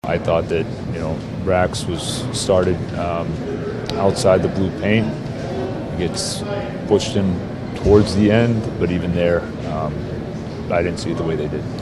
Muse says he still disagrees with the goaltender interference call against Rickard Rakell, and he’d challenge it again if he could.